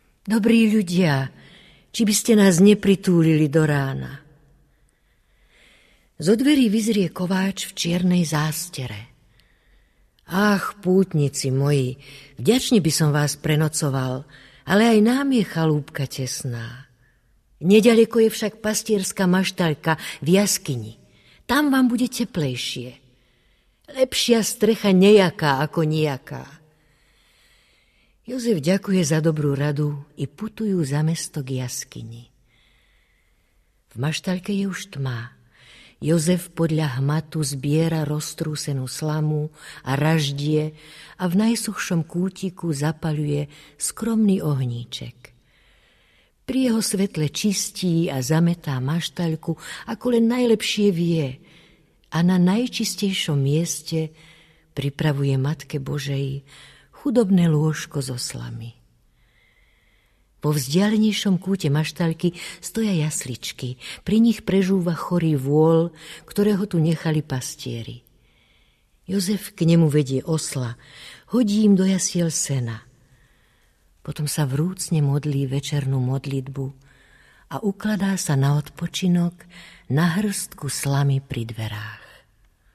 Pri vianočnom stromčeku audiokniha
pútavo prerozprávané láskavým hlasom Zdeny Grúberove